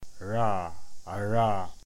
English: Pronunciation of a retroflex approximant, [ɻ]: [ɻa aɻa].